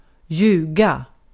Ääntäminen
IPA: /ˈjʉːˌɡa/